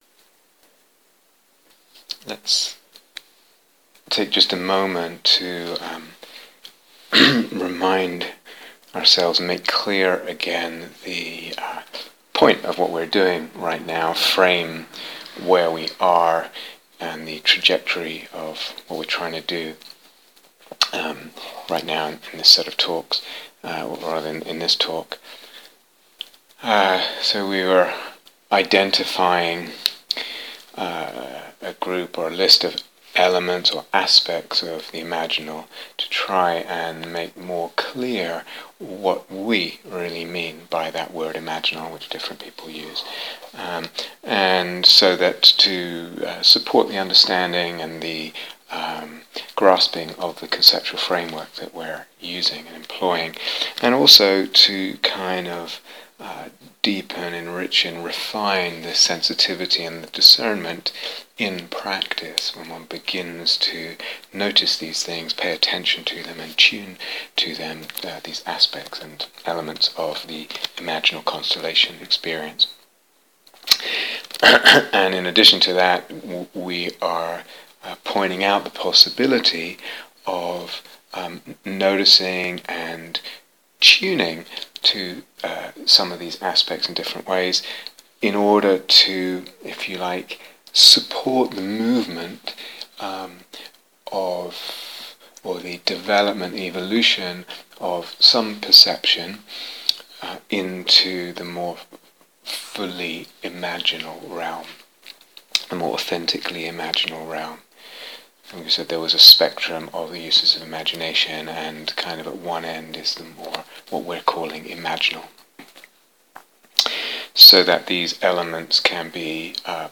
talks